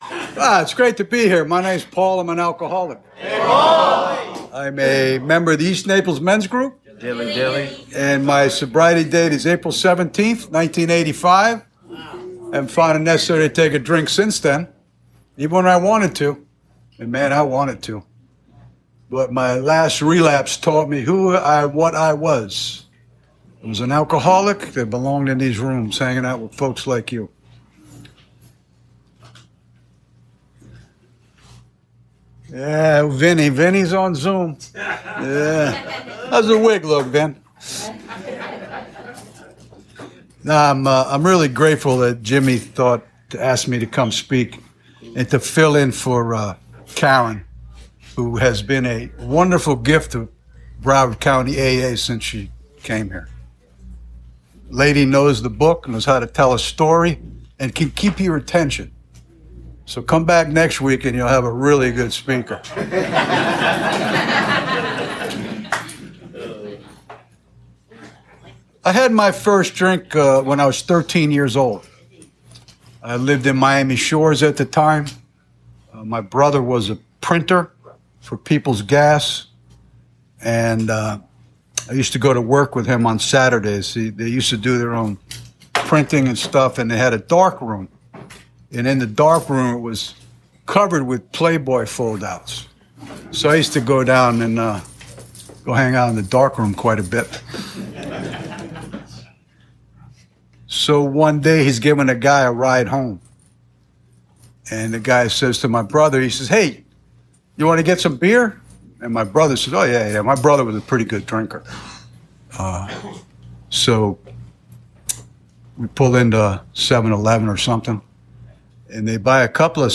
AA Speaker Recordings AA Step Series Recordings Book Study